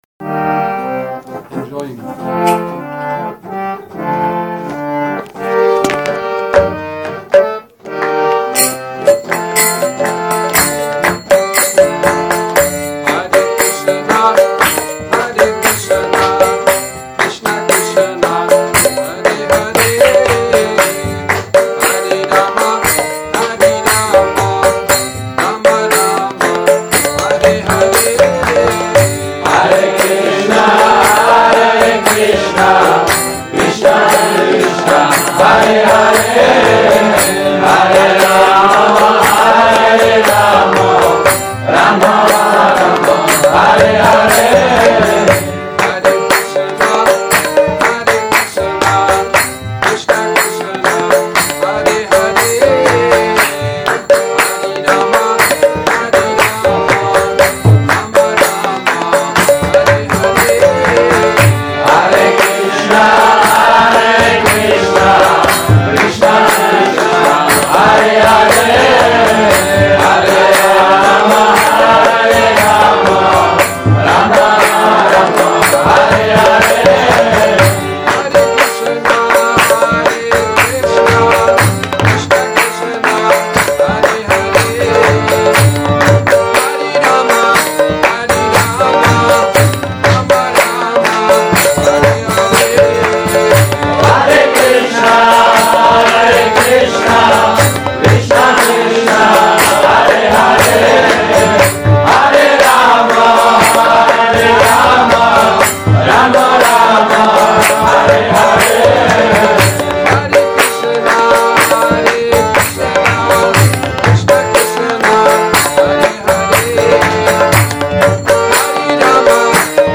Kírtan 2 – Šrí Šrí Nitái Navadvípačandra mandir